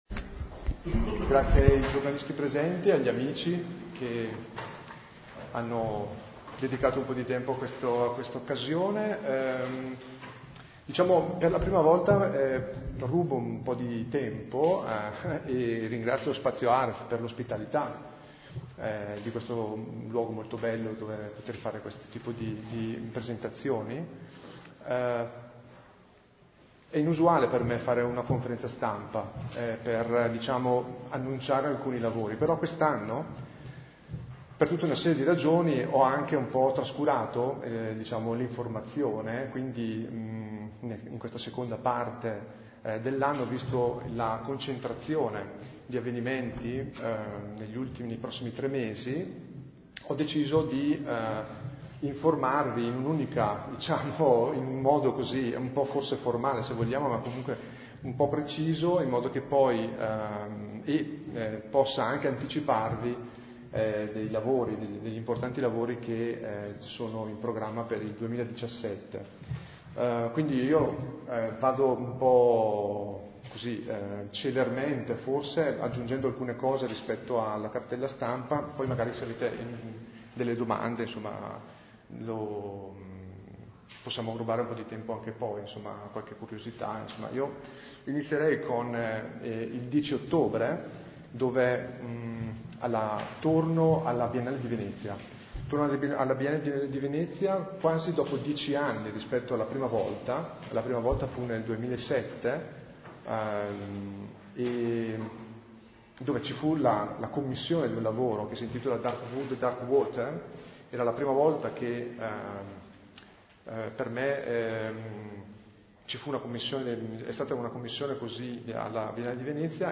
Conferenza stampa del 05 ottobre 2016